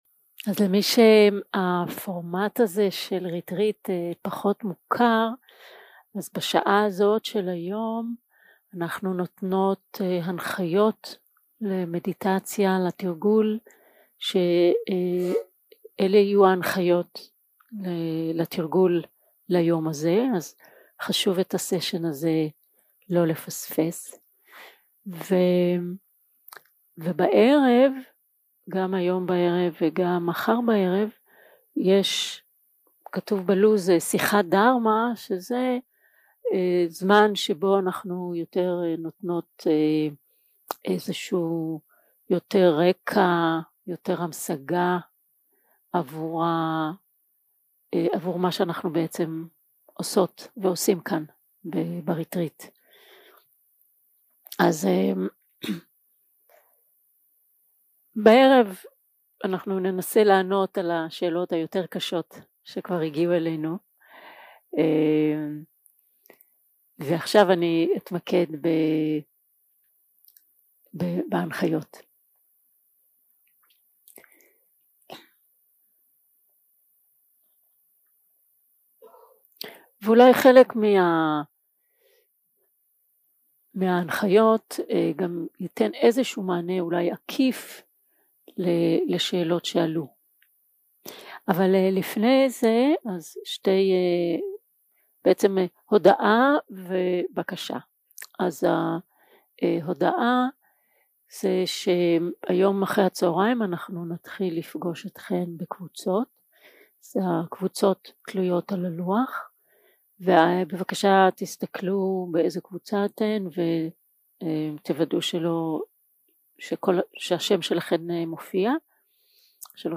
Guided meditation שפת ההקלטה